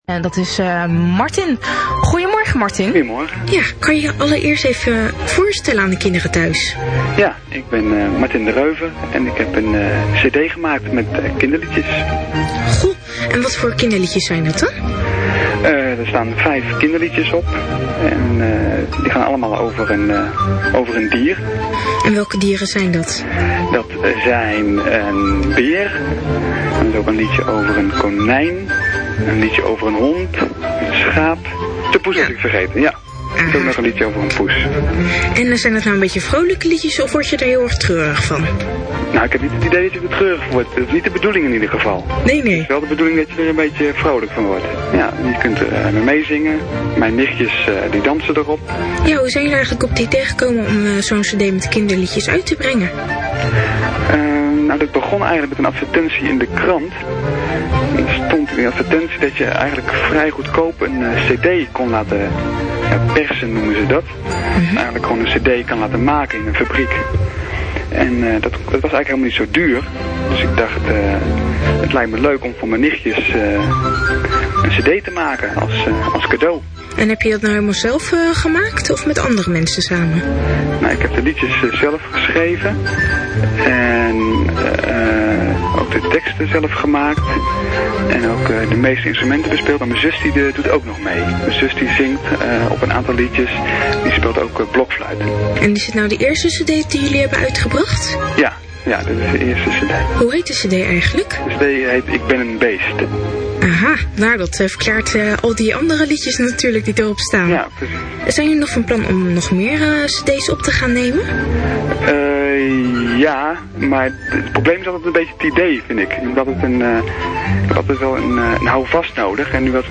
Interview Radio NRG (mp3): 782 kB / 3 min 19 sec
• De radio-interviews op deze pagina zijn mono met een bitsnelheid van 32 kb/s.